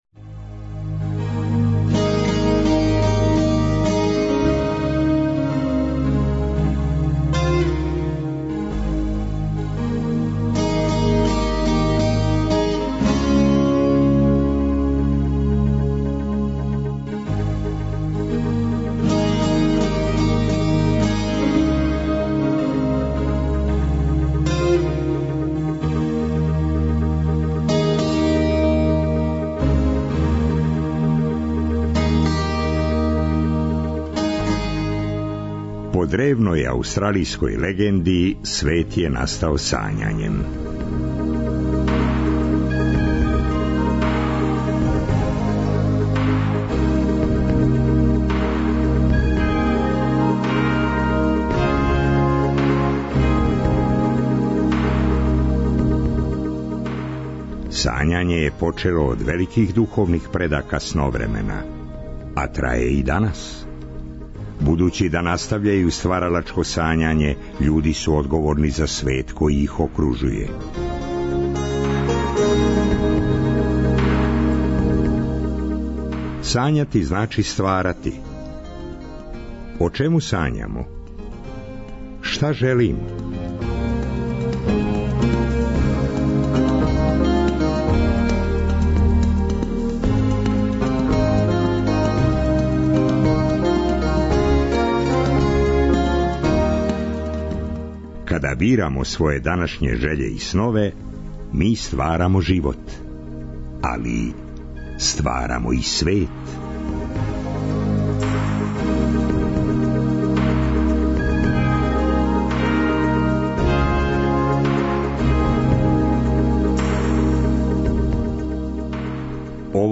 Занимљиве приче, добра музика, снови из Сновремена...